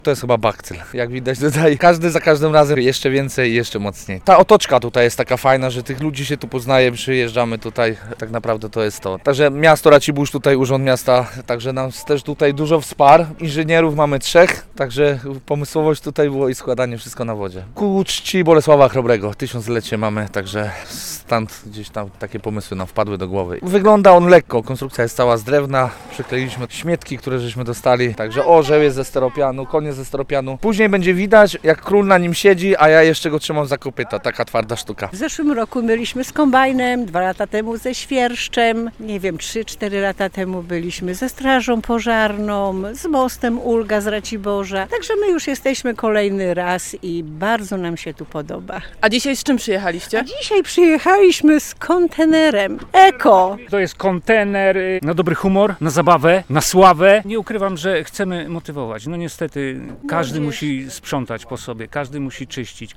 Pierwsze pływadła są już w Augustowie - relacja